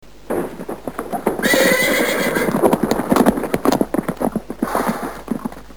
马车-交通工具-图秀网
图秀网马车频道，提供马车音频素材。